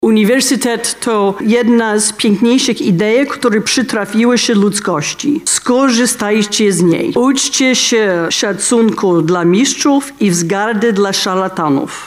Anne Applebaum, Agnieszka Holland i Olga Tokarczuk okolicznościowe dyplomy odebrały podczas uroczystości, która odbyła się wczoraj w auli uniwersyteckiej na Wydziale Prawa.